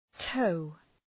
Προφορά
{təʋ}